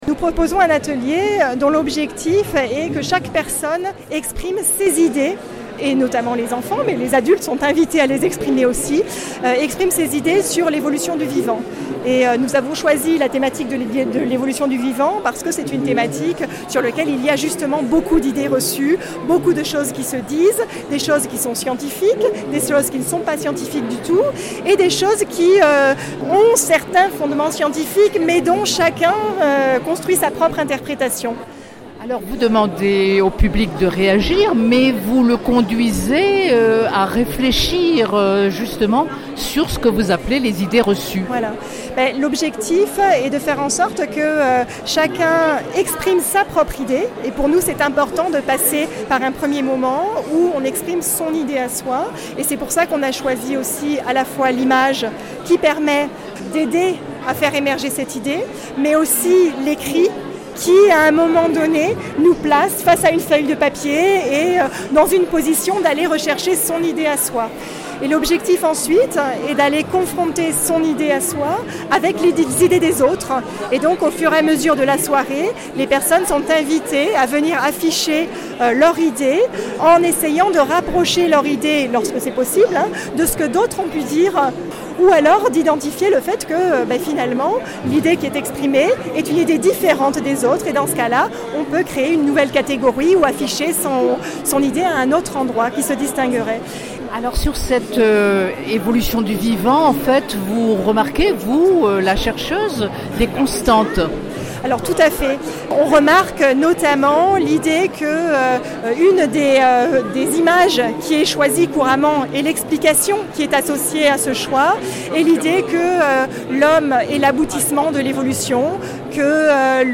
Nous sommes à l’entrée de la Nuit Européenne des Chercheurs 2016, organisée par Aix Marseille Université (AMU).